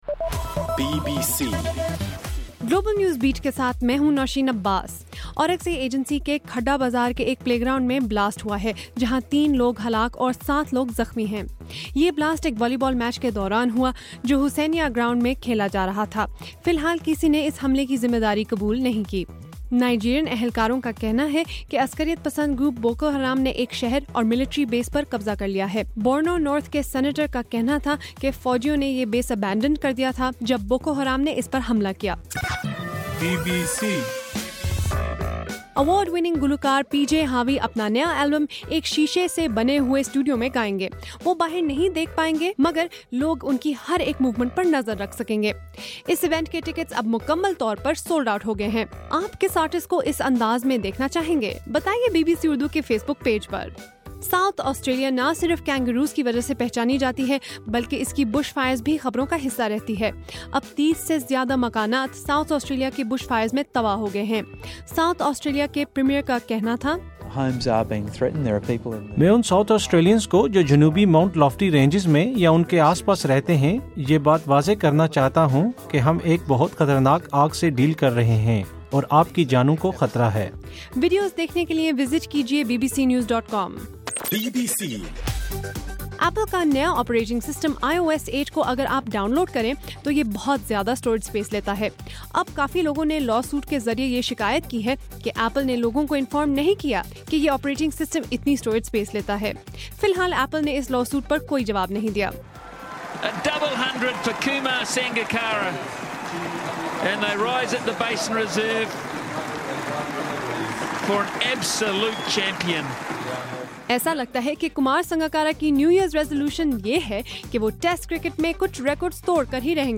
جنوری 4: رات 10 بجے کا گلوبل نیوز بیٹ بُلیٹن